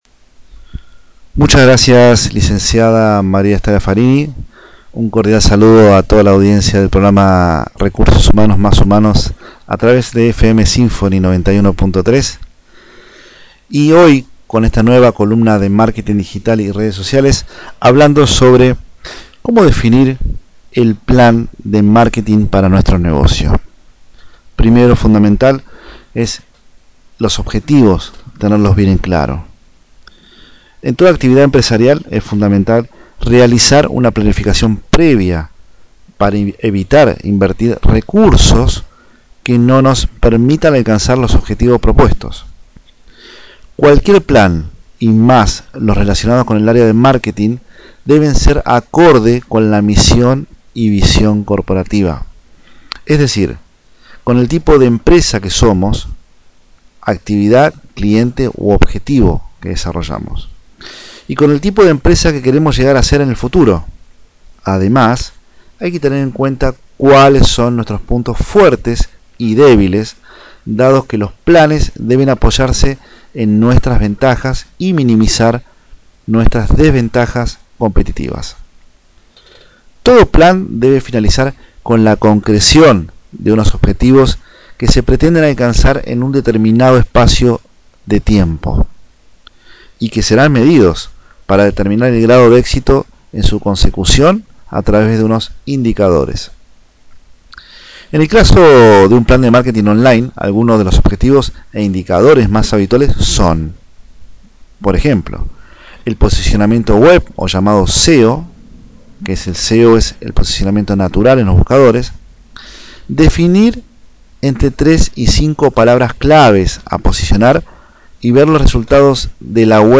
<< NUEVO AUDIO >> En esta nueva columna de Marketing Digital y Redes Sociales para el Programa Radial Recursos Humanos + Humanos » que se emite por Fm Simphony 91.3 el cual me gustaría compartir contigo Como Definir el Plan de Marketing para Tu Negocio